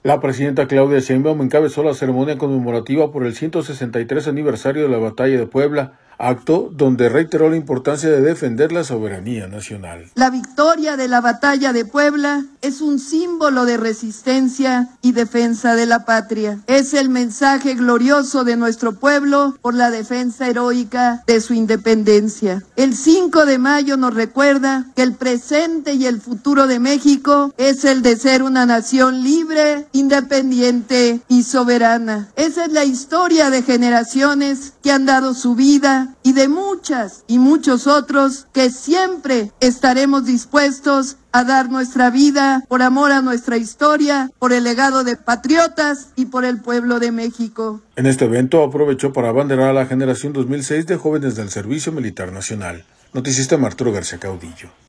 La presidenta Claudia Sheinbaum encabezó la ceremonia conmemorativa por el 163 aniversario de la Batalla de Puebla, acto donde reiteró la importancia de defender la soberanía nacional.